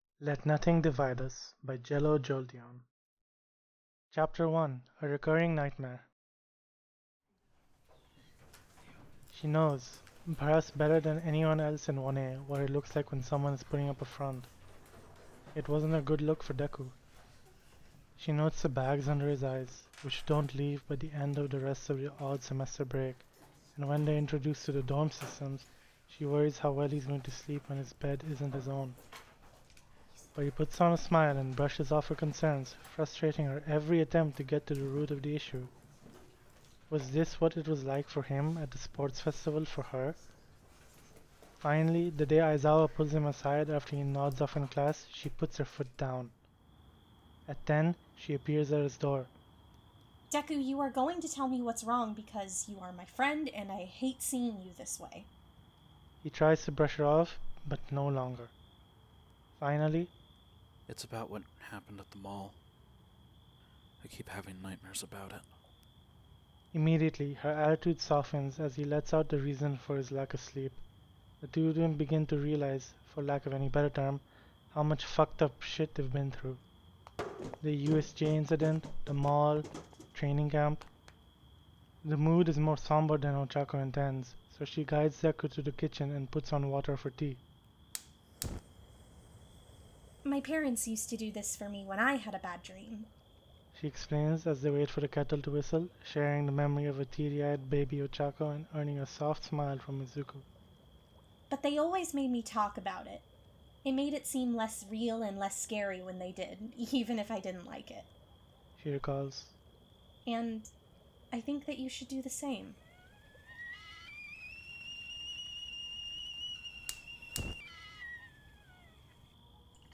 Podfics
Voice of Izuku Midoriya
Narrator Voice of Nurse [OC]
Voice of Ochako Uraraka